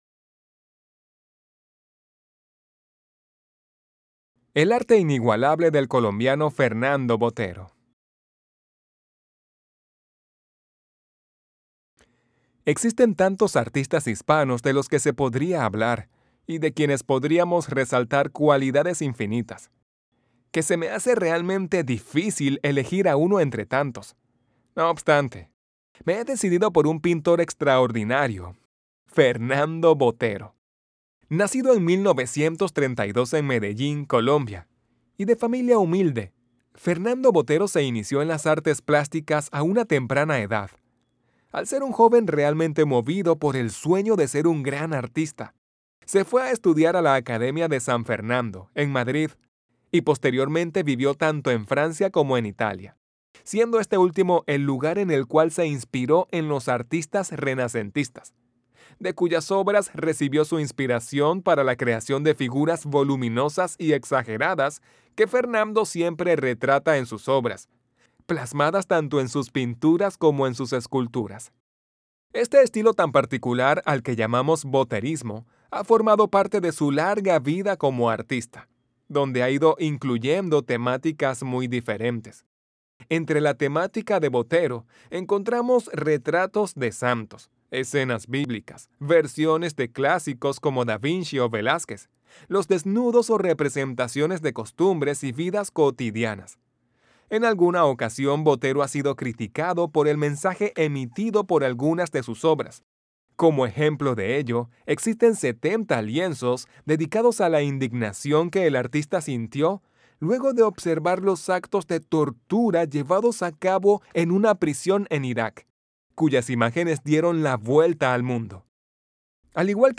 Spanish online reading and listening practice – level C1
audio by a Latin American voice professional